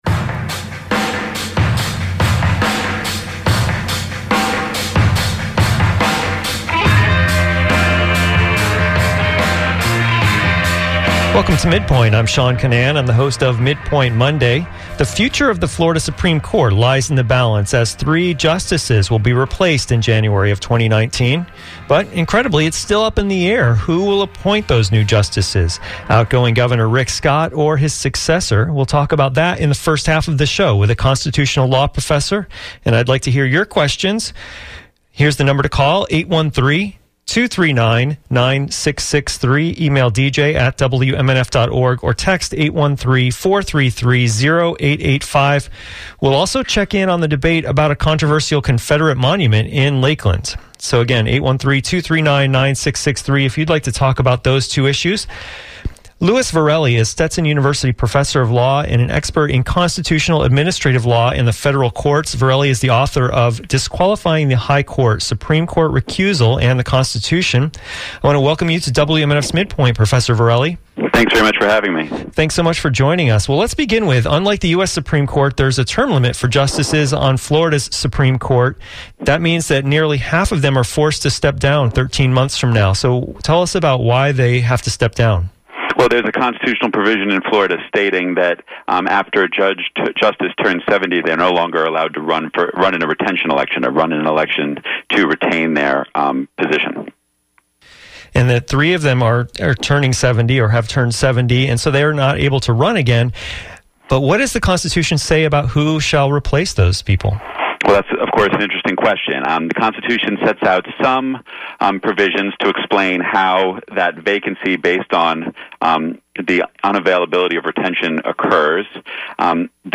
On the first half of WMNF’s MidPoint, we talk about that with a constitutional law professor.